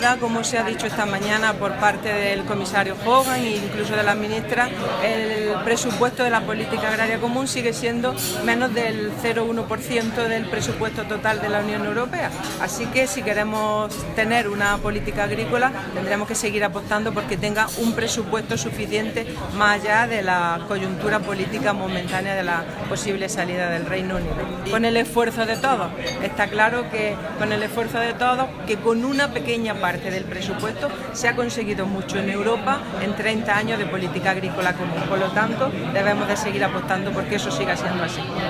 Declaraciones consejera pagos directos